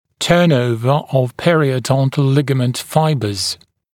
[‘tɜːnˌəuvə əv ˌperɪəu’dɔntl ‘lɪgəmənt ‘faɪbəz][‘тё:нˌоувэ ов ˌпэриоу’донтл ‘лигэмэнт ‘файбэз]перестройка волокон периодонтальной связки